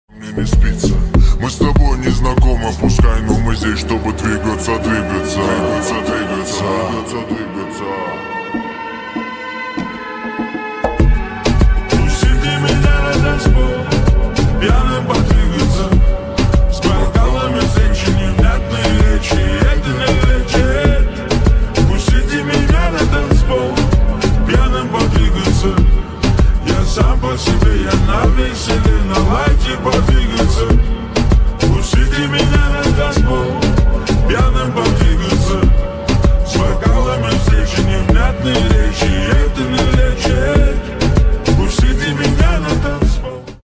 BMW M5 cs . . sound effects free download